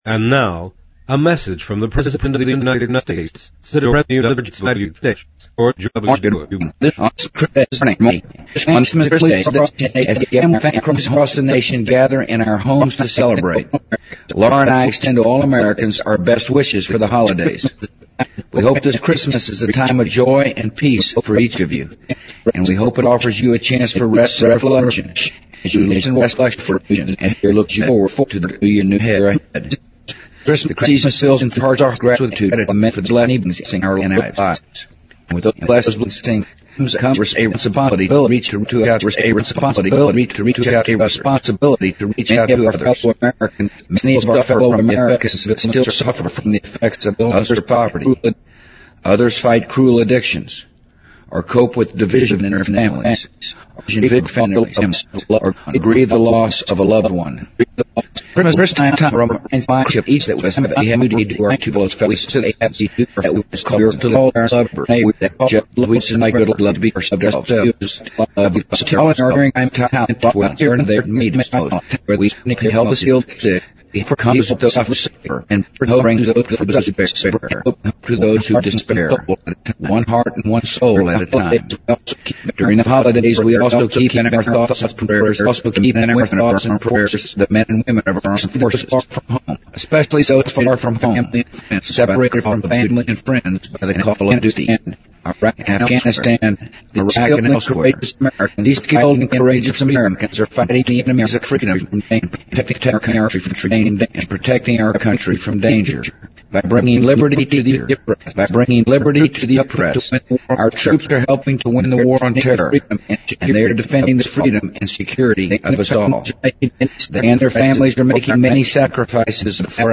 President Bush-2004-12-25电台演说 听力文件下载—在线英语听力室